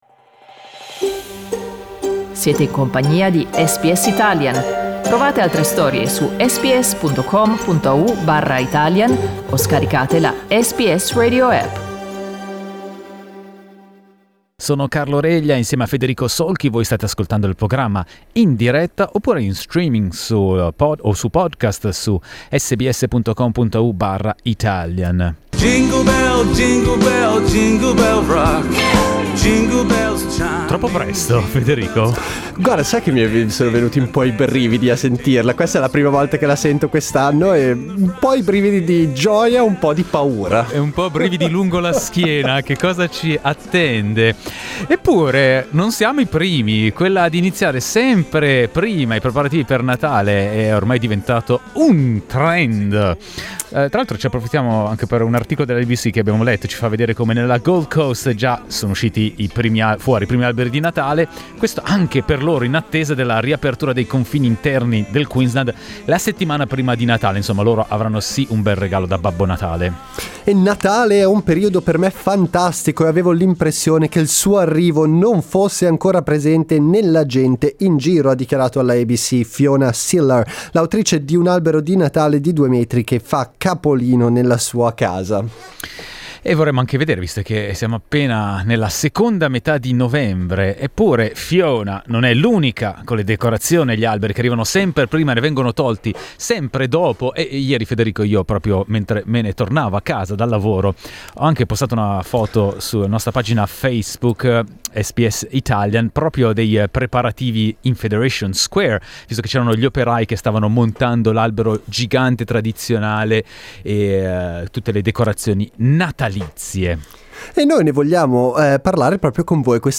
SKIP ADVERTISEMENT Ecco cosa ne pensano gli ascoltatori e le ascoltatrici di SBS Italian.